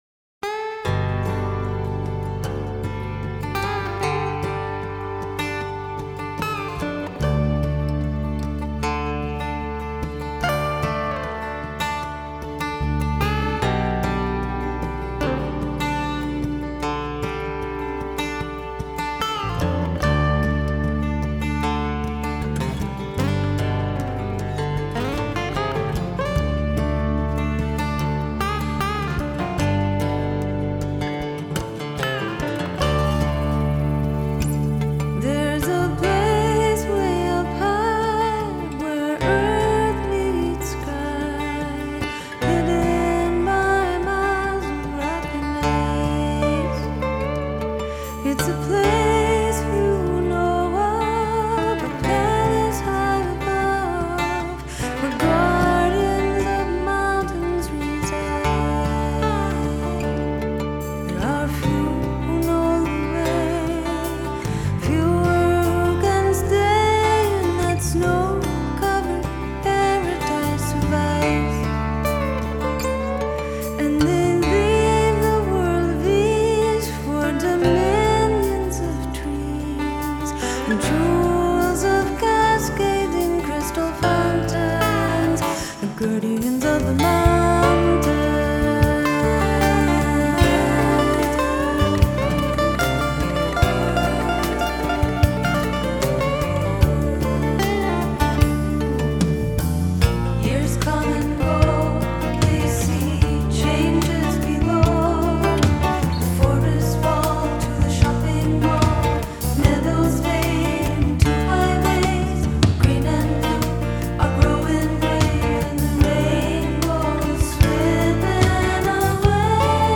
pop rock group